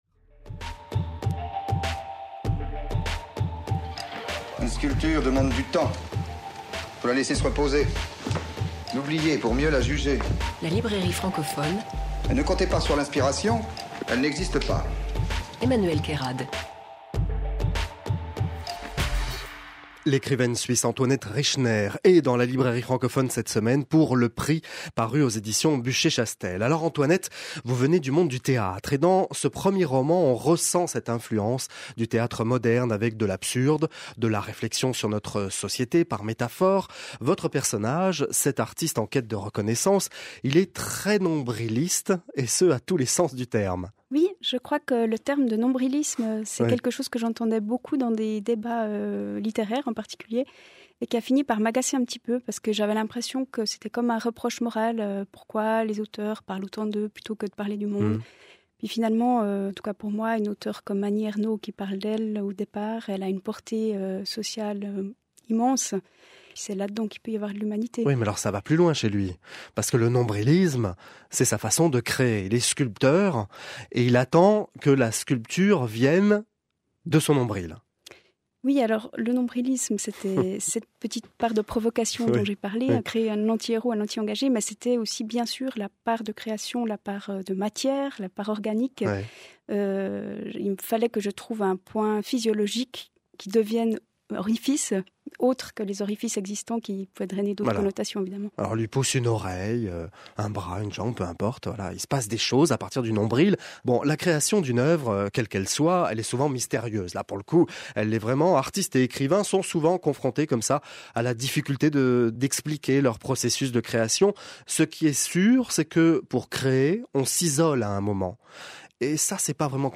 Entretiens et critiques radio :